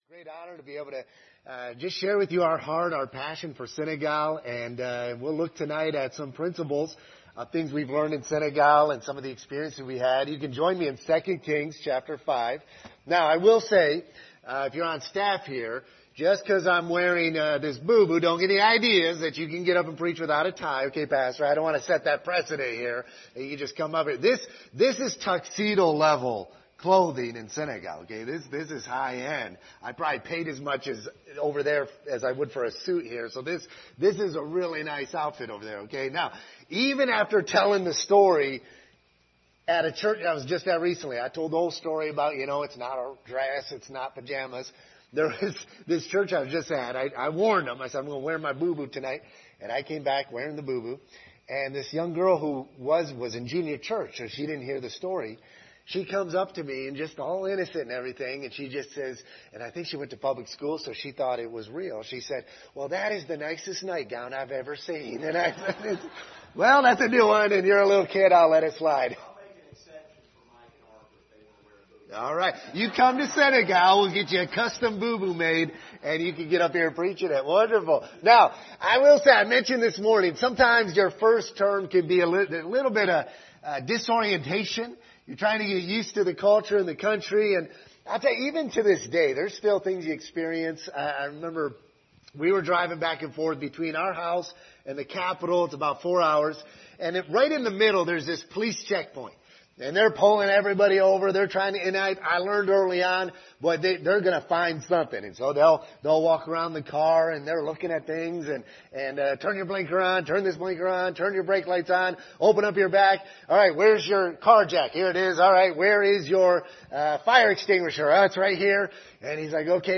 Series: 2023 Missions Conference Passage: 2 Kings 5 Service Type: Special Service